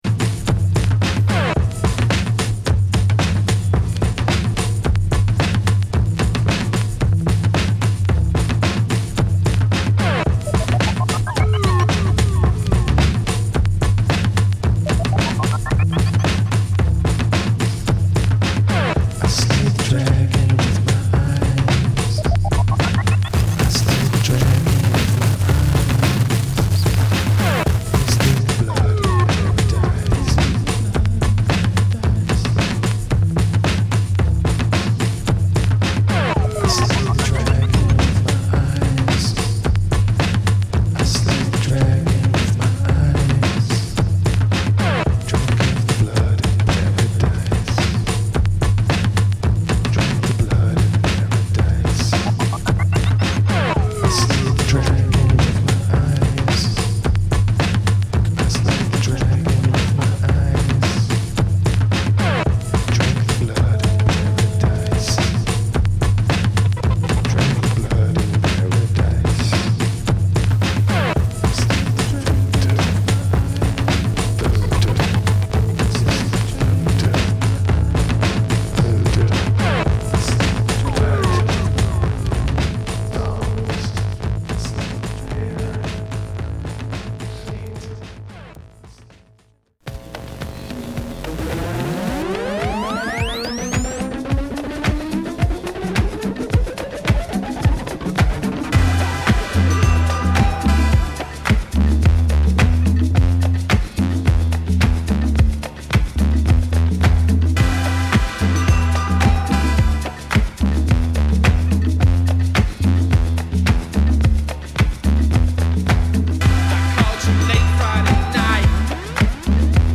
Crossover